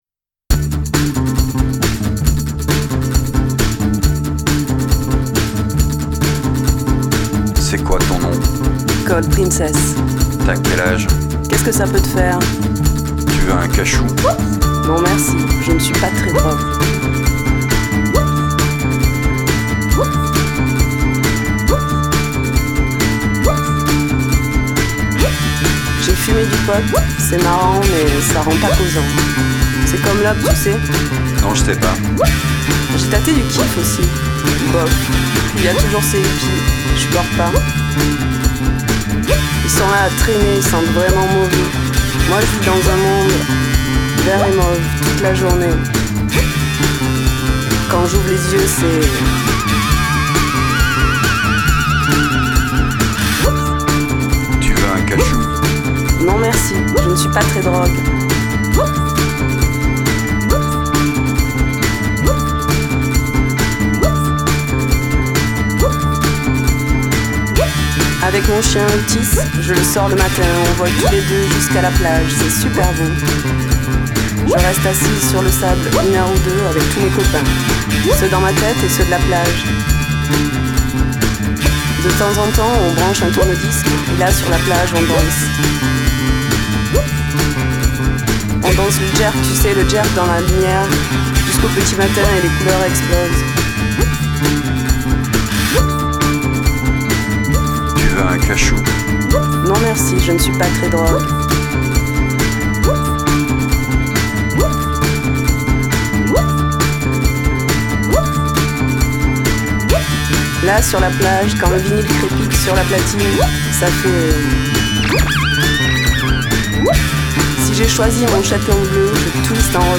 Genre: World, French Pop, Indie, Chanson